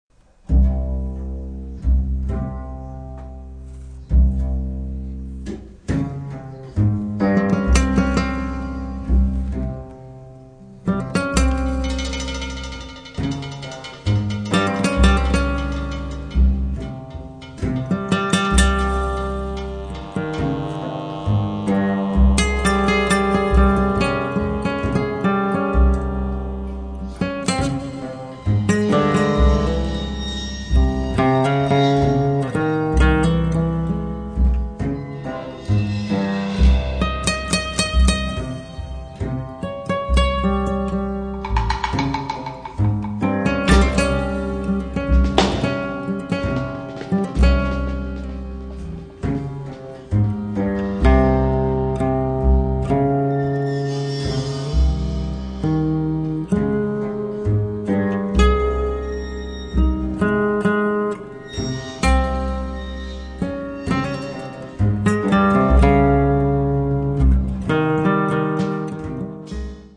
chitarra
fisarmonica
contrabbasso
batteria